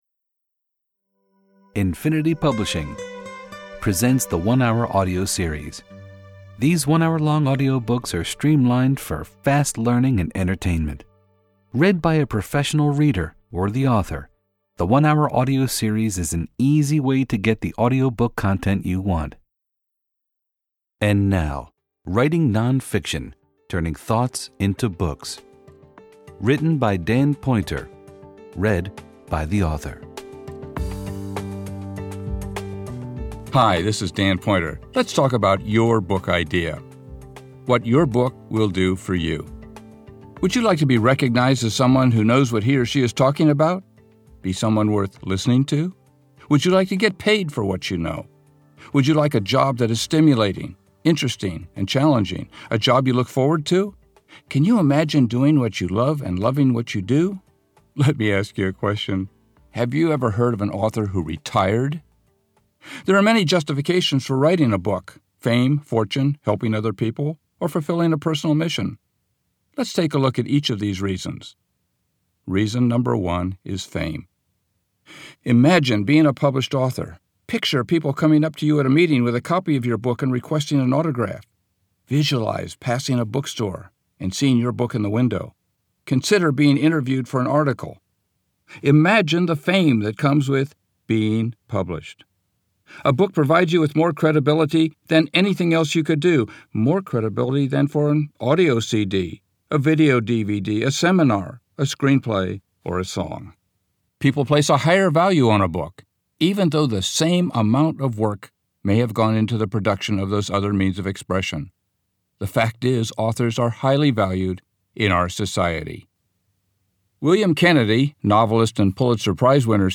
Audio book